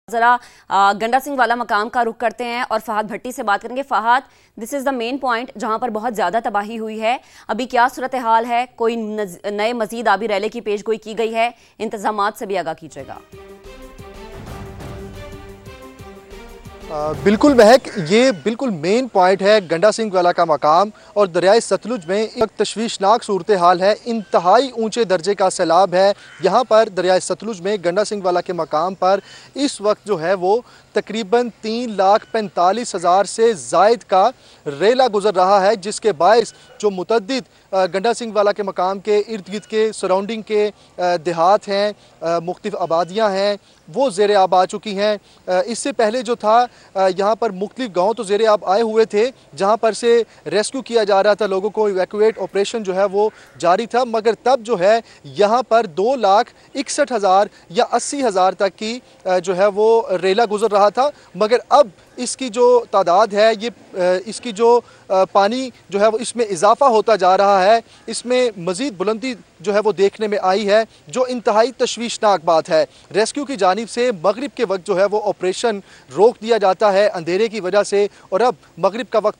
Breaking News